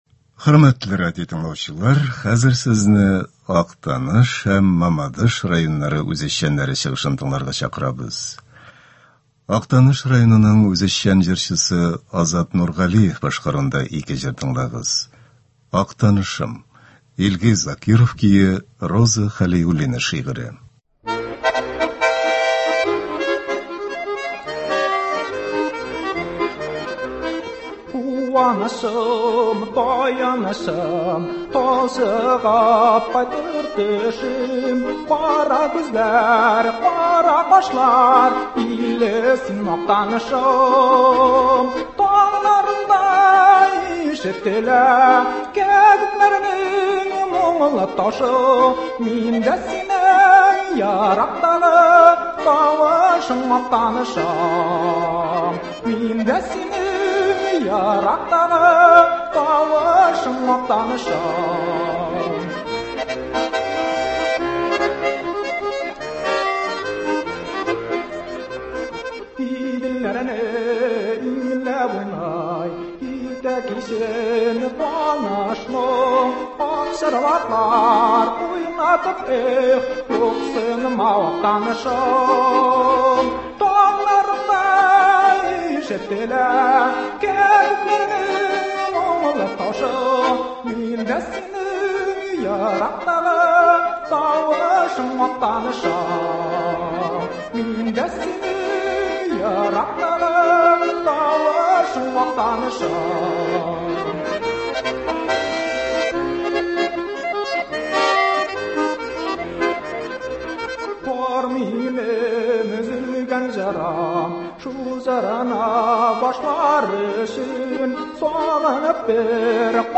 Үзешчән башкаручылар чыгышы.
Концерт (22.07.24)